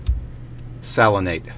[sal·i·nate]